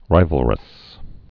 (rīvəl-rəs)